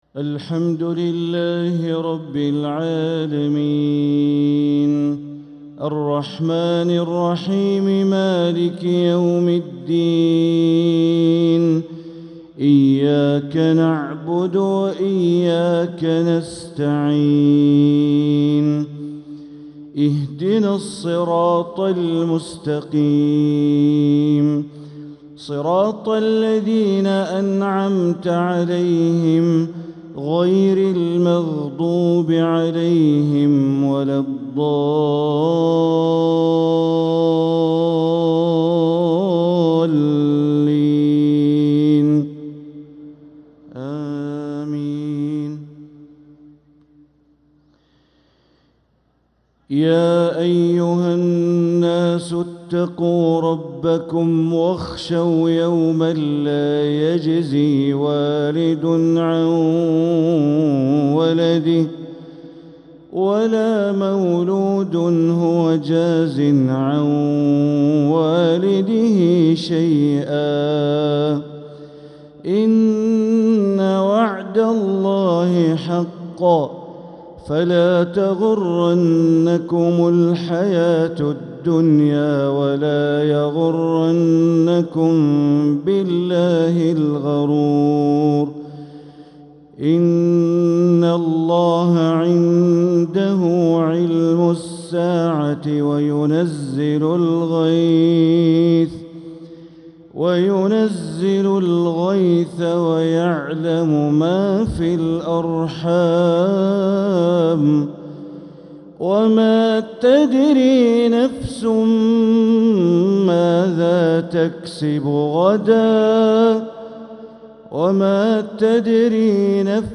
Maghrib prayer from Surat Luqman & Fatir 8-2-2025 > 1446 > Prayers - Bandar Baleela Recitations